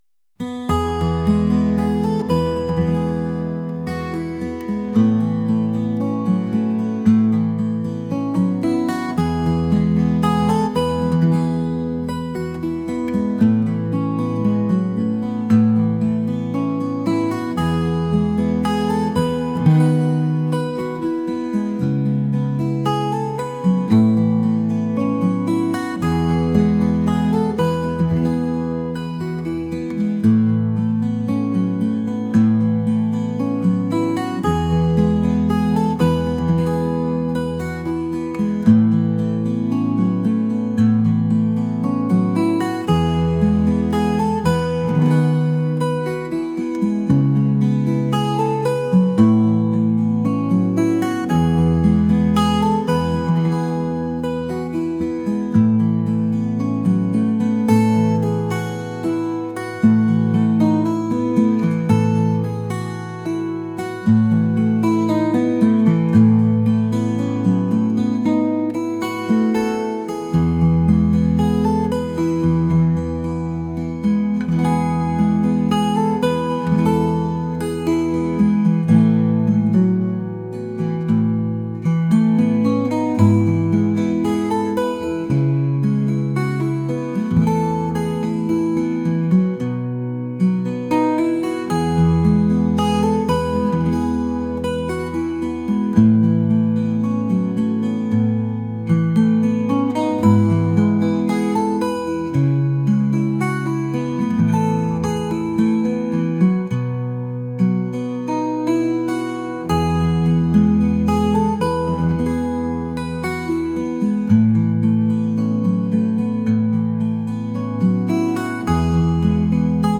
folk | acoustic | ambient